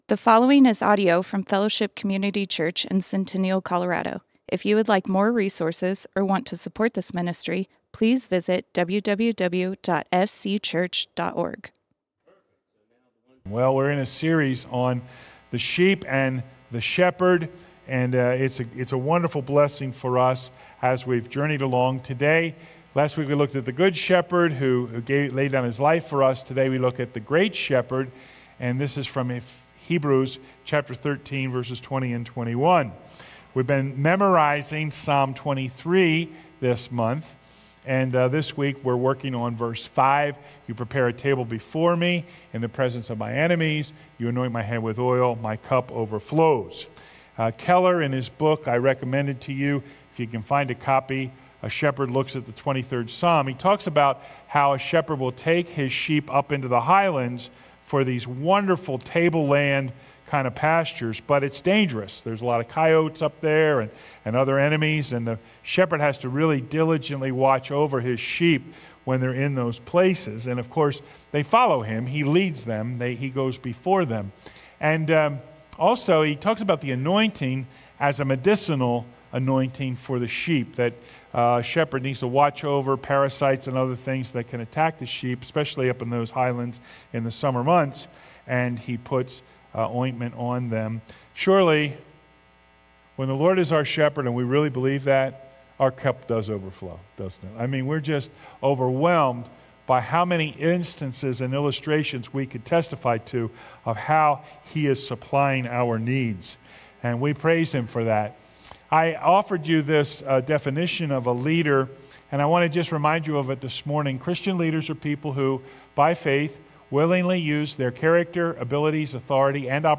Fellowship Community Church - Sermons Our Great Shepherd Play Episode Pause Episode Mute/Unmute Episode Rewind 10 Seconds 1x Fast Forward 30 seconds 00:00 / 26:50 Subscribe Share RSS Feed Share Link Embed